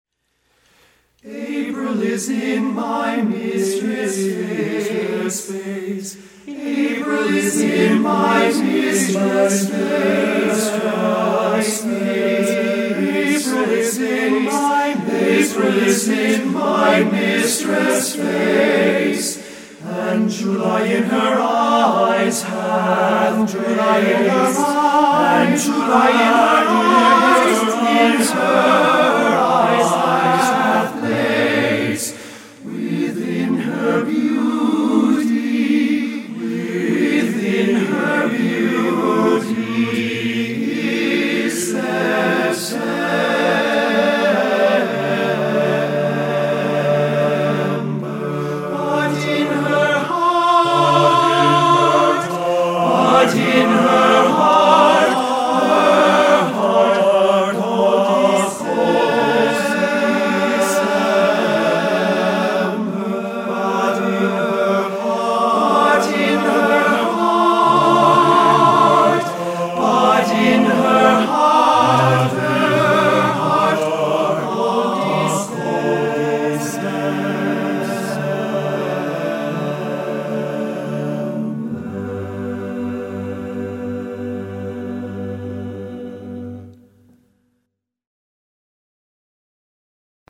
Voicing: TBB a cappella